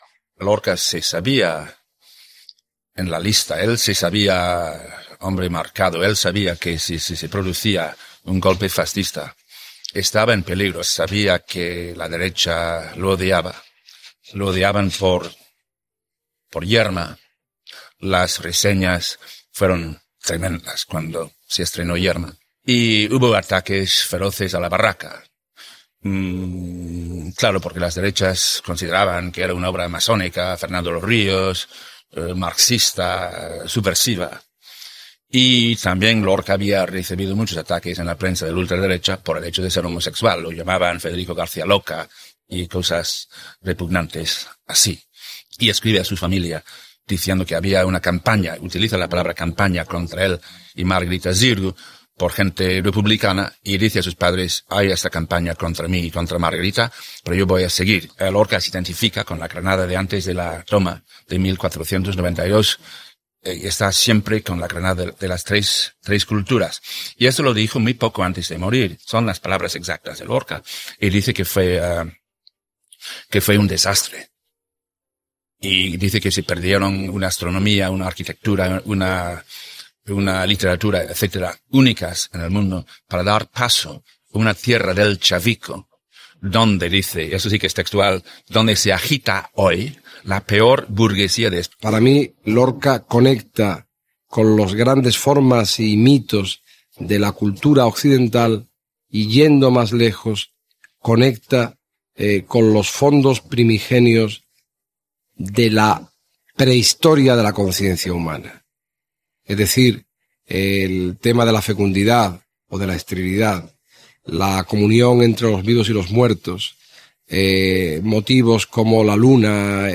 L'hispanista Ian Gibson i el críticliterari Miguel García Posada rememoren la figura del poeta Federico García Lorca en el centenari del seu naixement